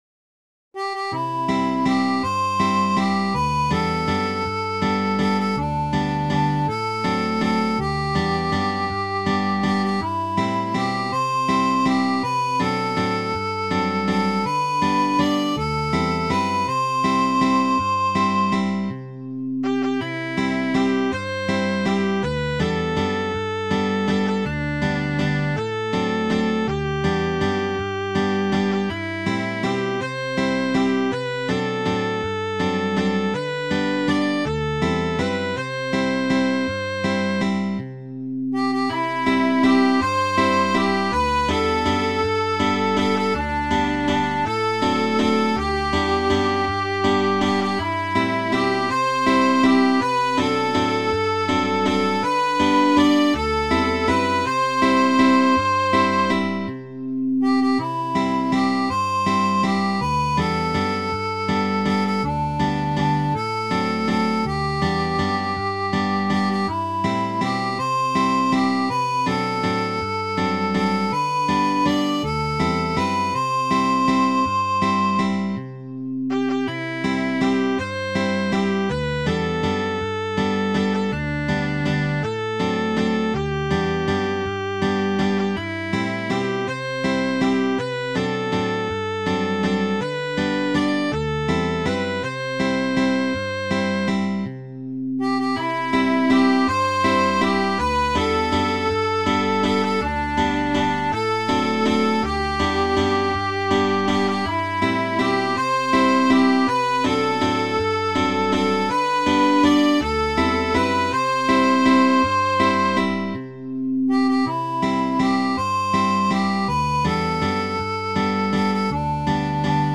Midi File, Lyrics and Information to Cowboy Jack